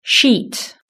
Index of /platform/shared/global-exercises/pron-tool/british-english/sound/words